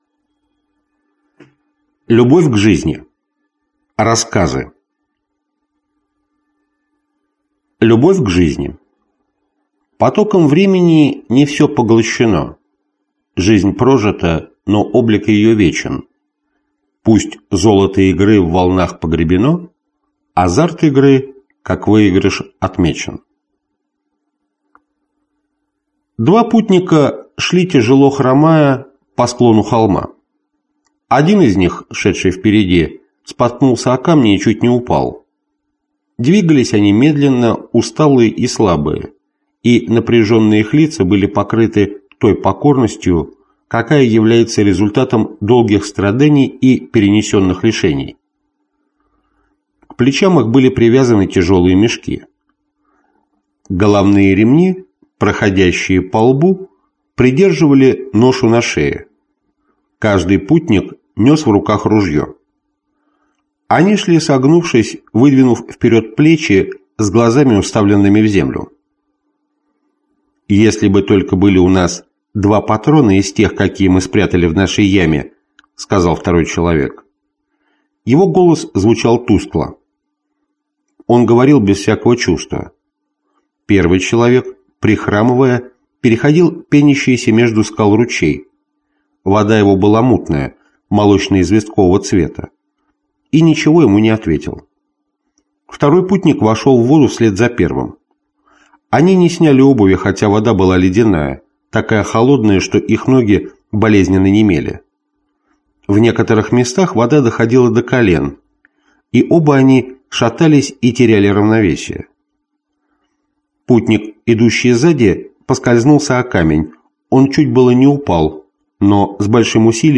Аудиокнига Сказки южных морей | Библиотека аудиокниг